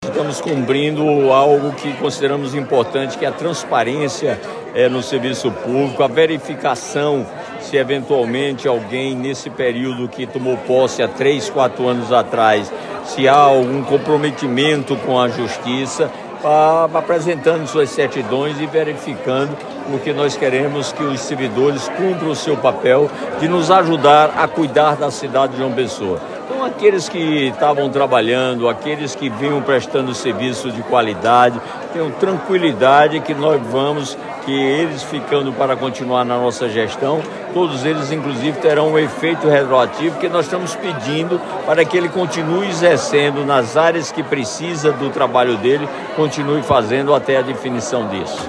Os comentários de Cícero foram registrados pelo programa Correio Debate, da 98 FM, de João Pessoa, nesta quarta-feira (08/01).